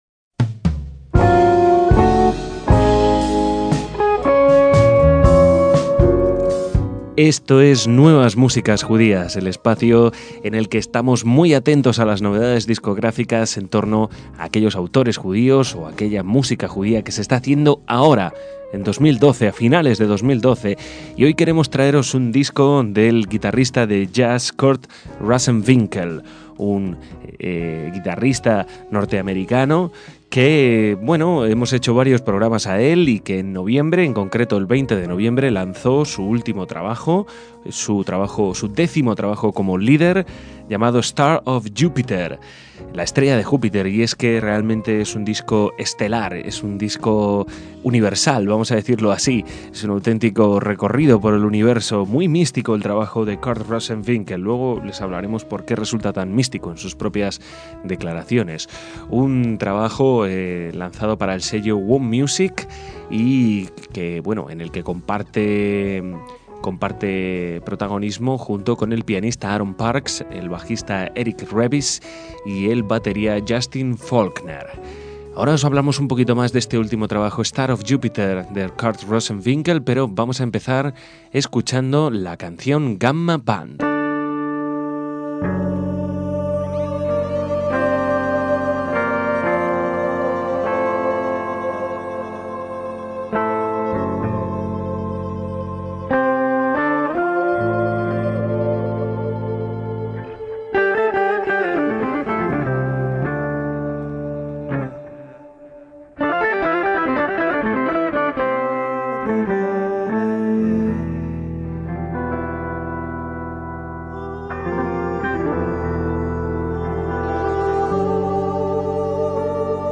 guitarrista de jazz
al piano
al contrabajo
a la batería